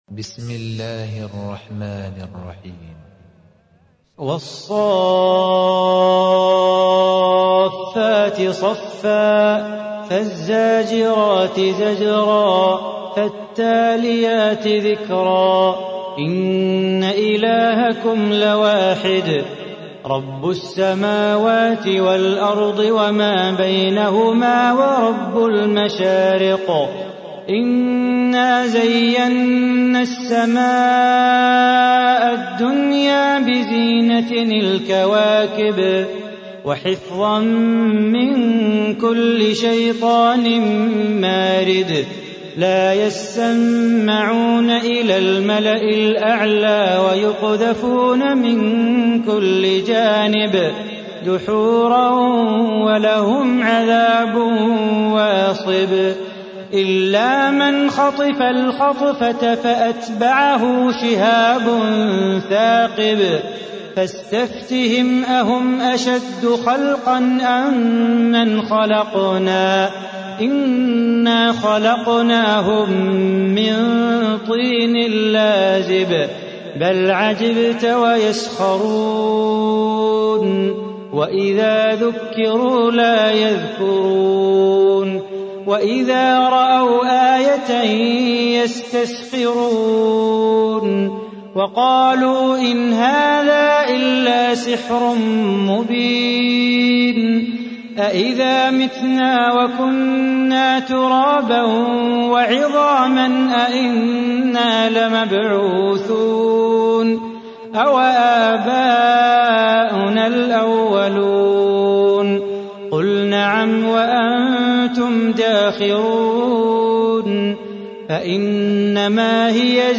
تحميل : 37. سورة الصافات / القارئ صلاح بو خاطر / القرآن الكريم / موقع يا حسين